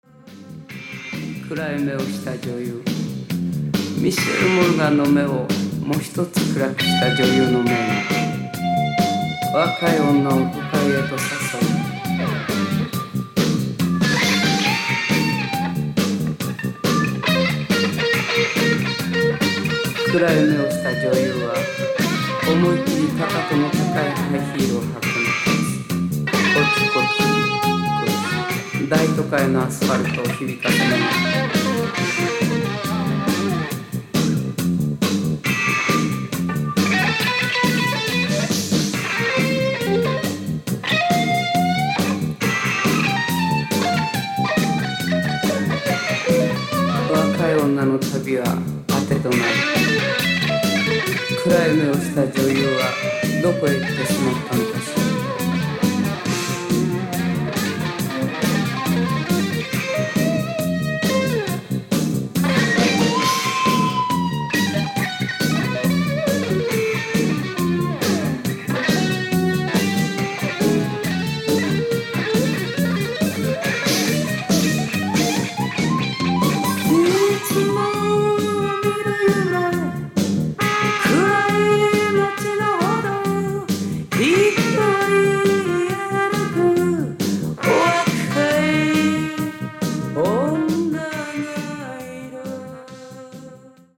Alto Saxophone, Flute
Electric Bass
Drums, Percussion